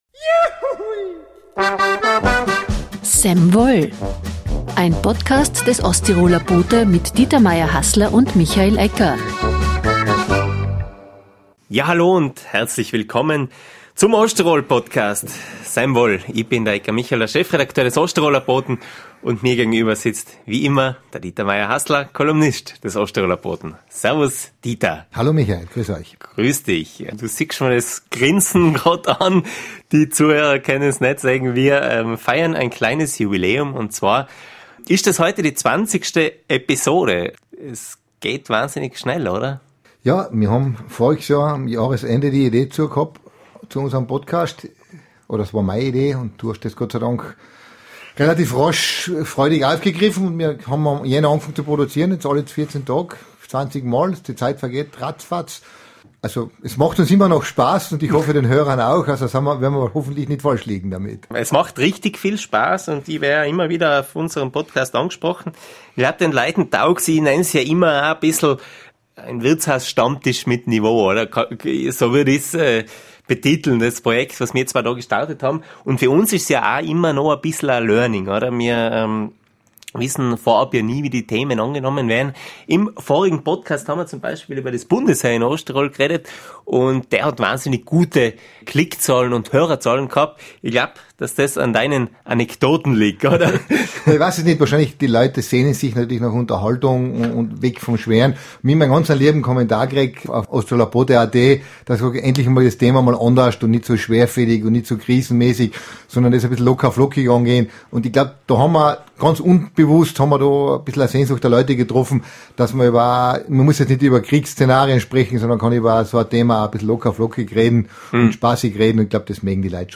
Osttirols Betriebe tun sich schwer, ihre Arbeitsplätze zu besetzen. Ohne Zuzug wird die Bevölkerung schrumpfen. Ein Gespräch über Migration.